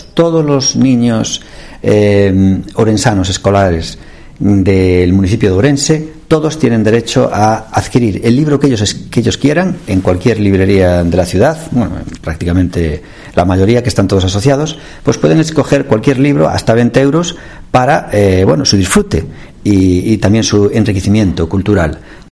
El alcalde de Ourense en la presentación de la primera edición